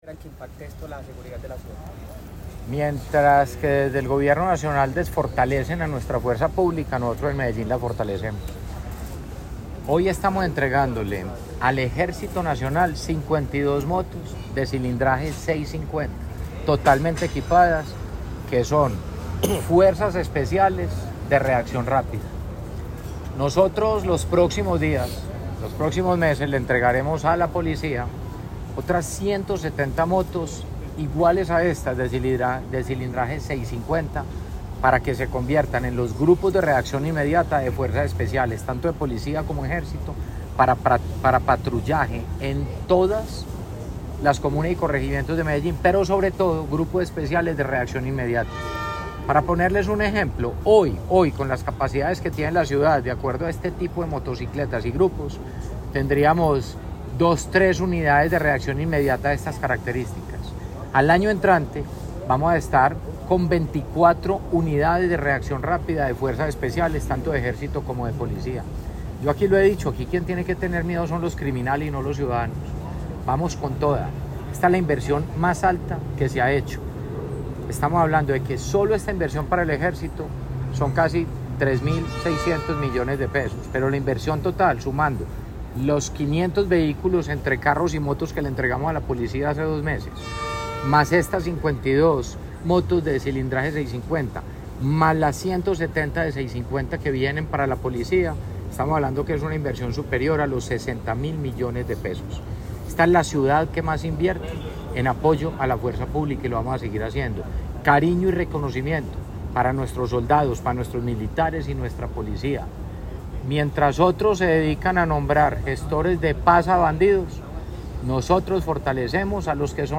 Declaraciones-alcalde-de-Medellin-Federico-Gutierrez-4.mp3